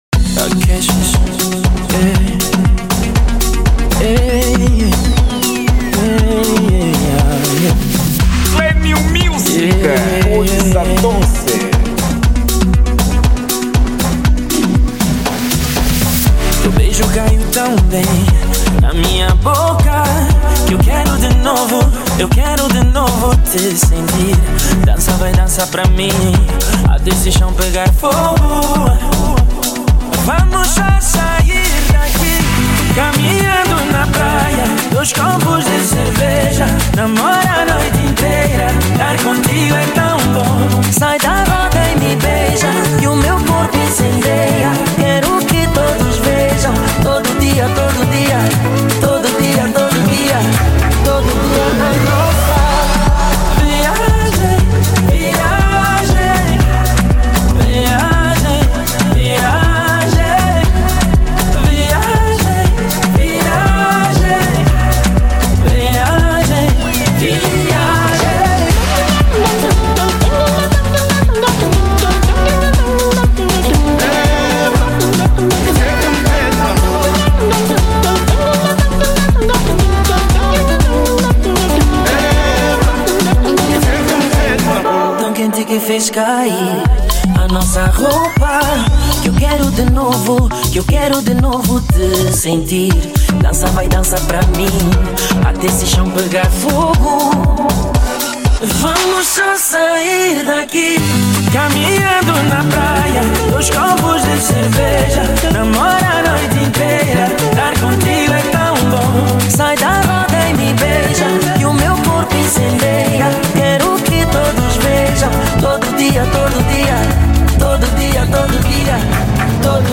Género: Afro Pop